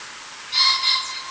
call1.wav